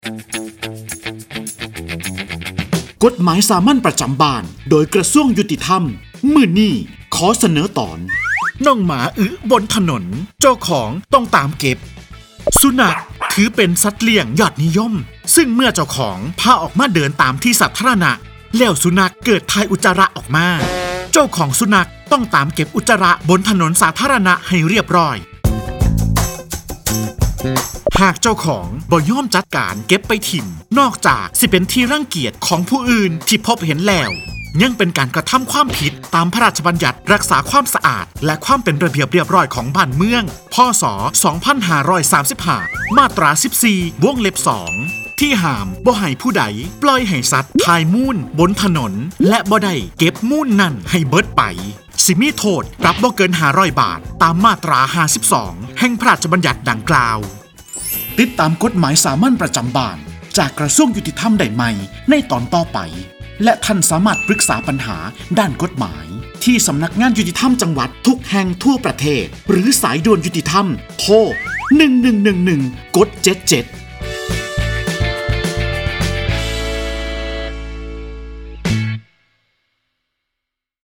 กฎหมายสามัญประจำบ้าน ฉบับภาษาท้องถิ่น ภาคอีสาน ตอนน้องหมาอึตามถนน เจ้าของต้องตาม
ลักษณะของสื่อ :   คลิปเสียง, บรรยาย